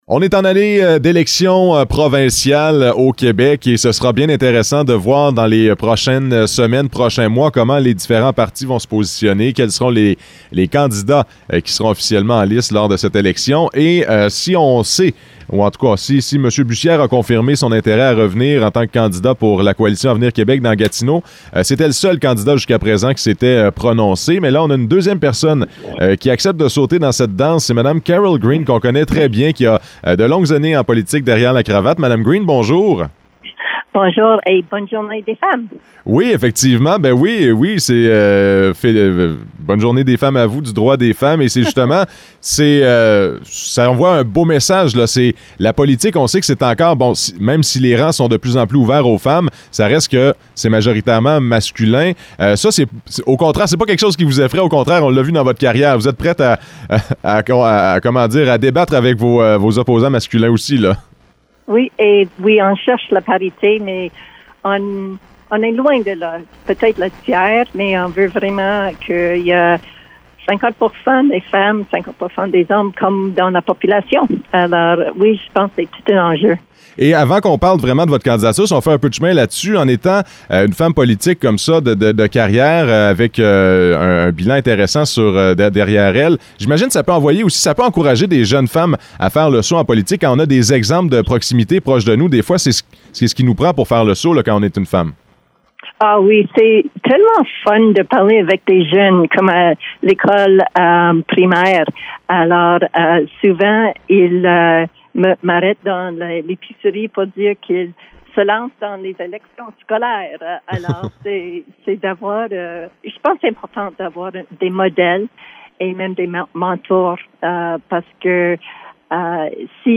Entrevue avec Caryl Green, candidate à l’investiture libérale dans la circonscription de Gatineau
Entrevues